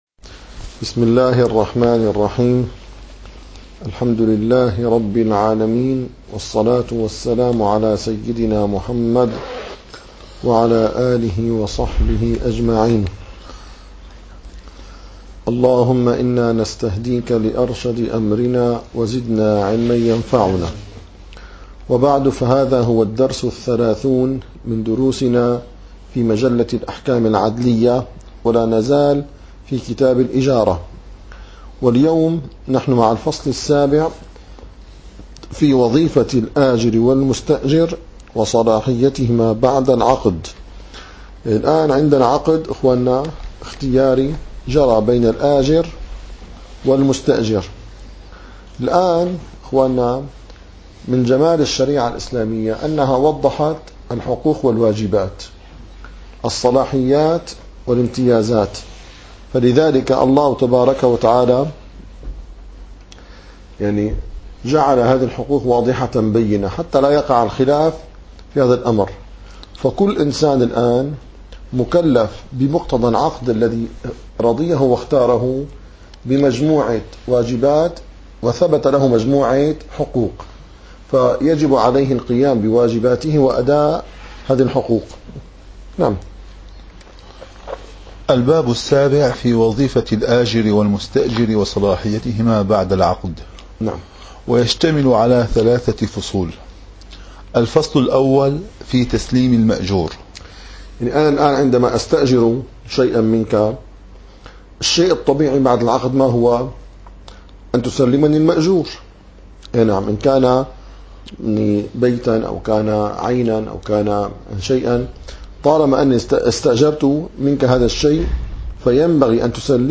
- الدروس العلمية - الفقه الحنفي - مجلة الأحكام العادلية - 30- مادة 582 تسليم المأجور